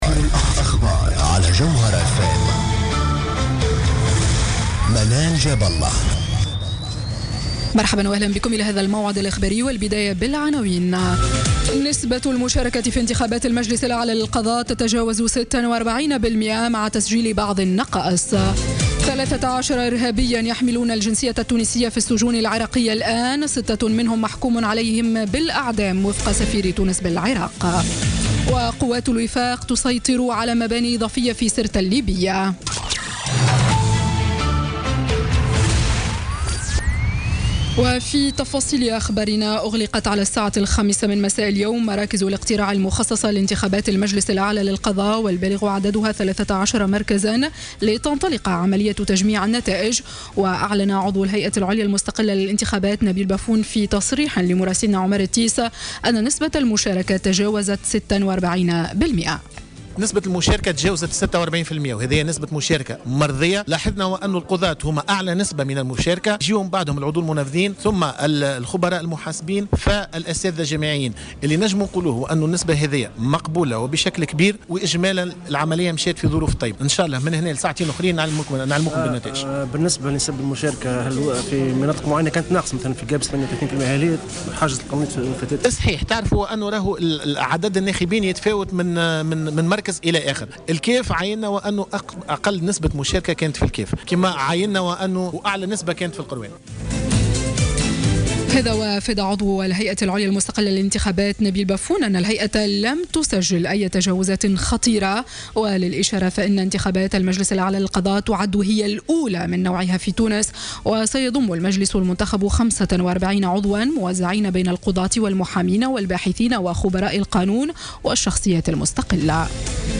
نشرة أخبار السابعة مساء ليوم الأحد 23 أكتوبر 2016